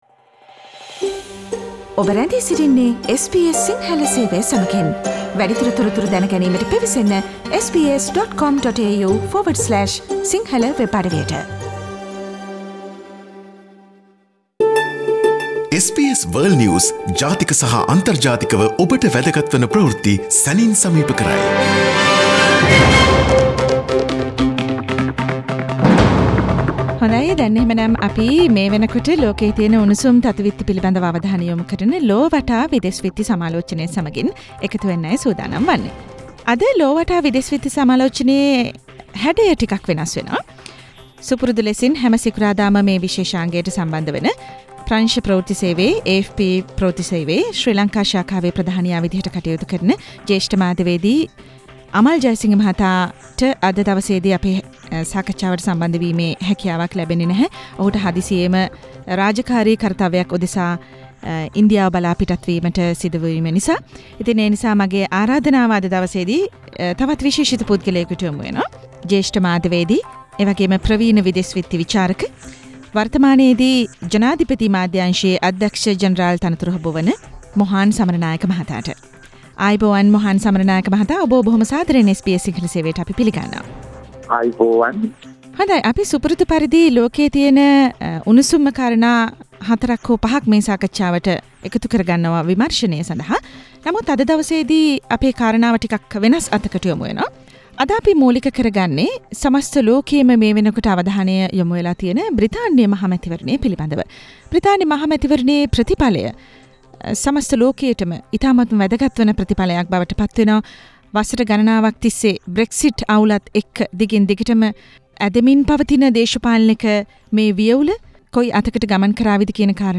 SBS සිංහල සේවය සමඟ සිදුකල සාකච්චාව